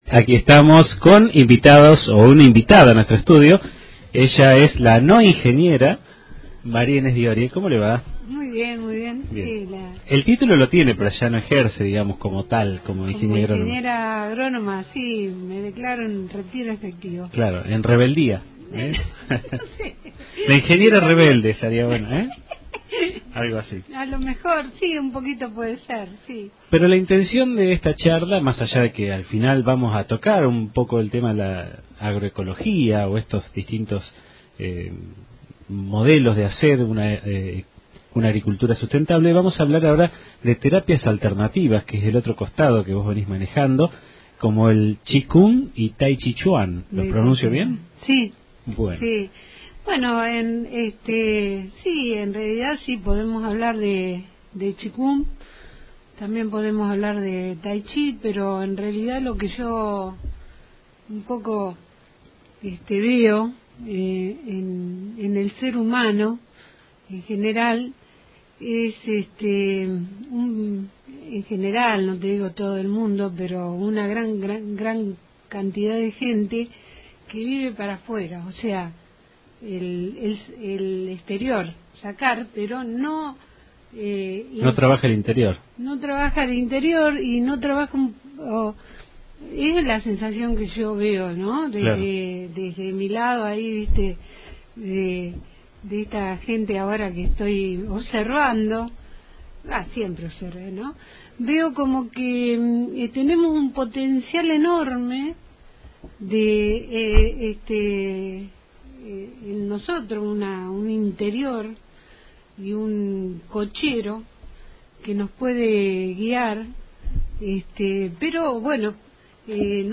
habló en Radio Rojas sobre las bondades de estas terapias alternativas.